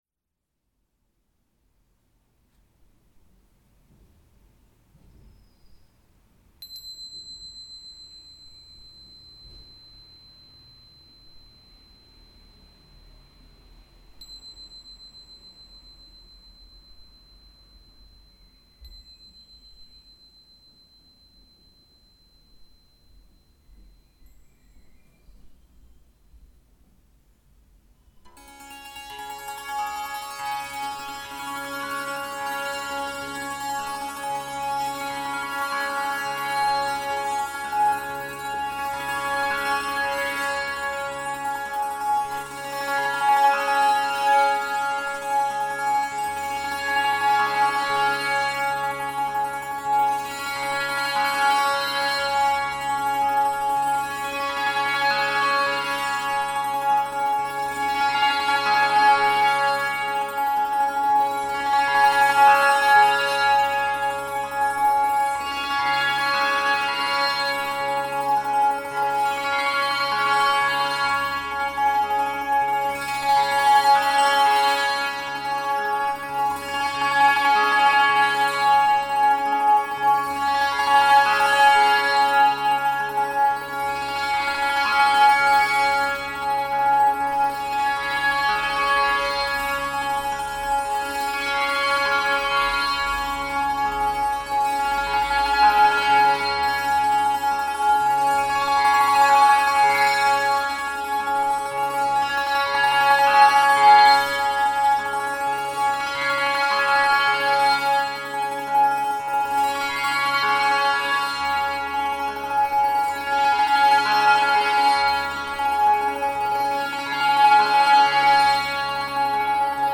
Um nachzuweisen, welche positiven Auswirkungen meine Klänge auf Wasser haben, spielte ich extra ein Stück für werdende Mütter und ungeborene Kinder.